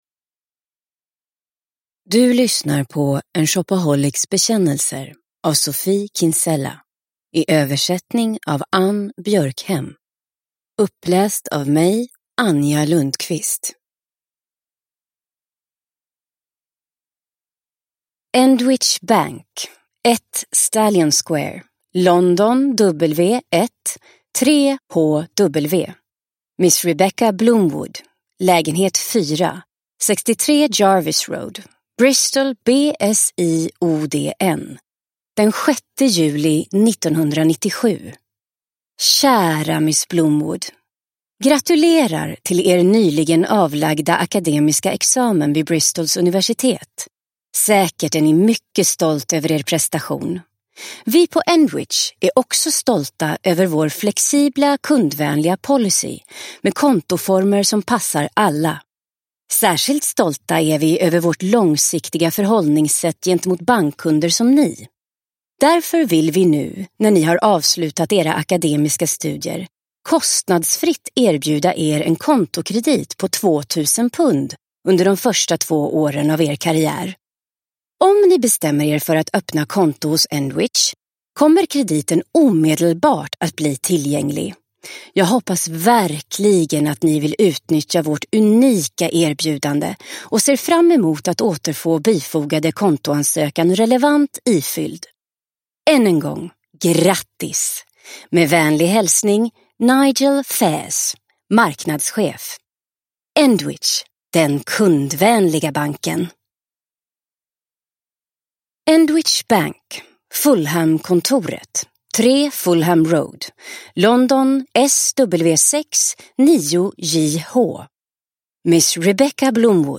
En shopaholics bekännelser – Ljudbok – Laddas ner